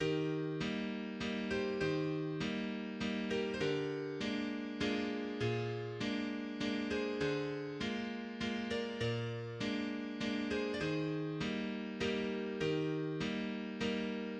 valzer di Johann Strauss jr
Valzer 1